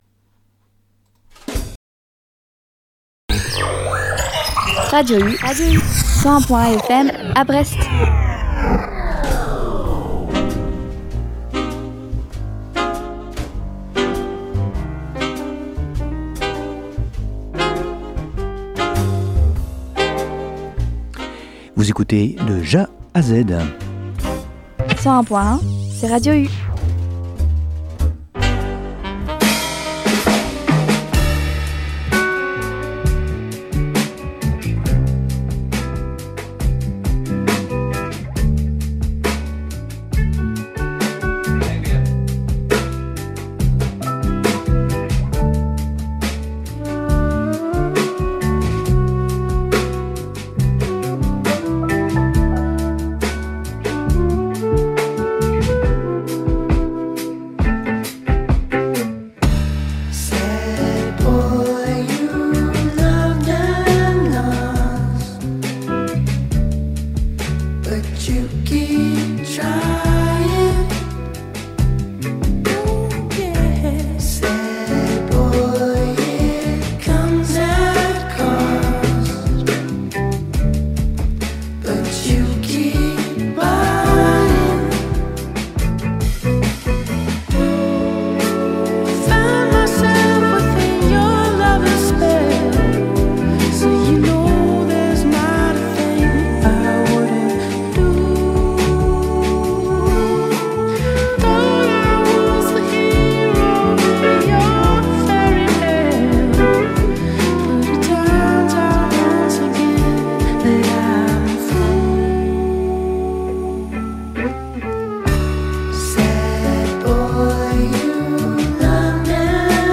Retrouvez ma sélection Jazz de Janvier